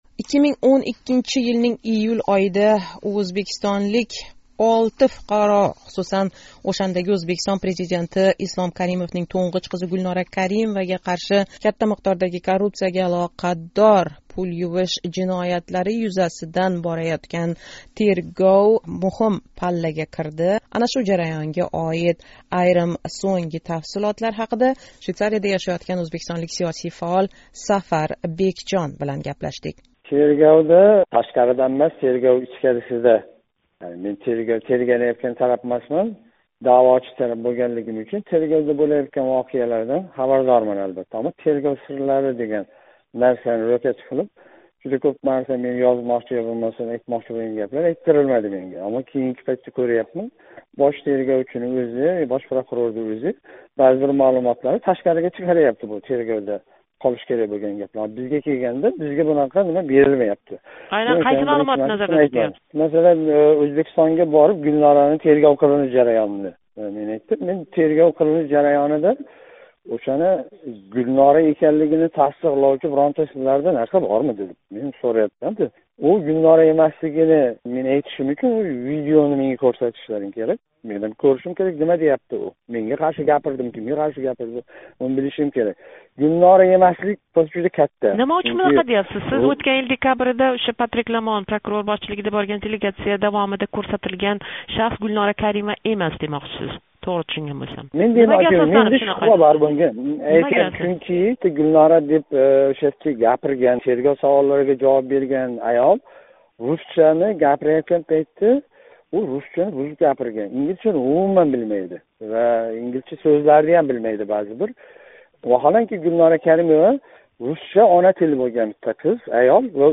Унинг Озодликка берган суҳбатида айтишича, Ўзбекистон марҳум президентининг тўнғич қизи Гулнора Каримовада кейинги 25 йил давомида Ўзбекистон бойликларини ўғирлаб келаëтган 90 та оилага оид махфий ҳужжатлар мавжуд.